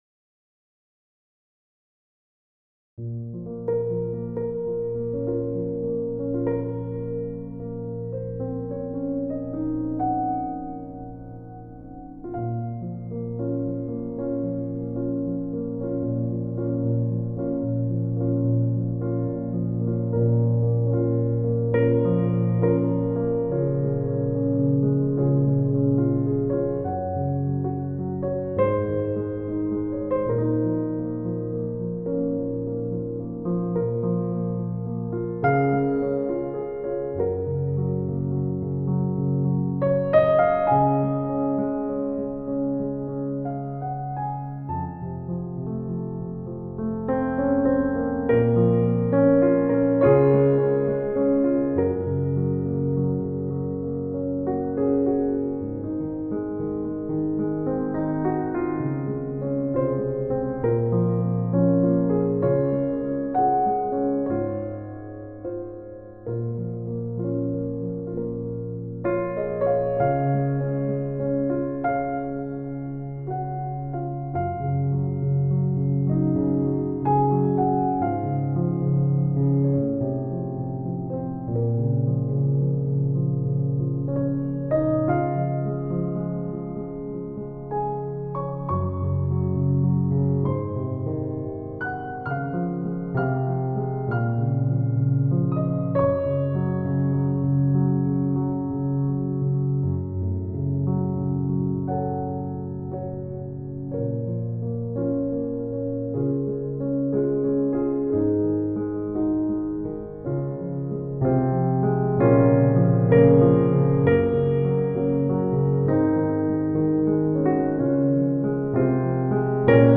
hi all I’m kind of miserable tonight so when I picked up my keyboard this is what came out improvised and unedited, as usual
hi all I’m kind of miserable tonight so when I picked up my keyboard this is what came out